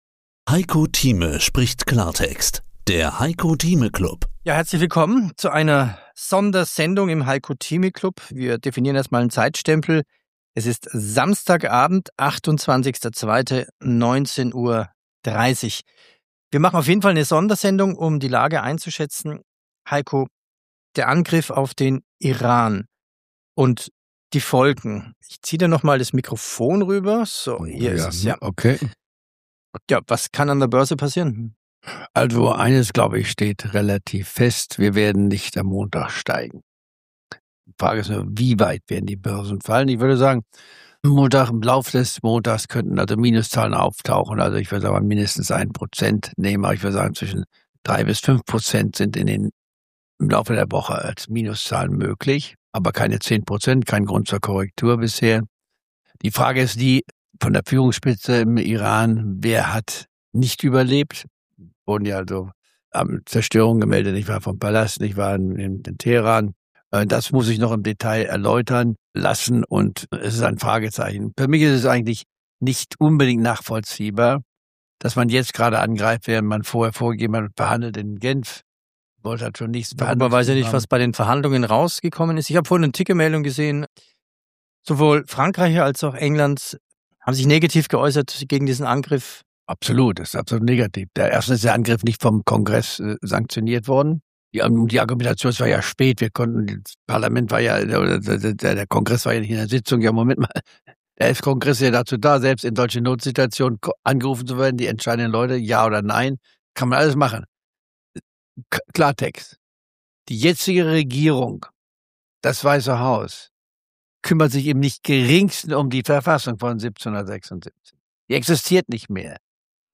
Live aus Dubai: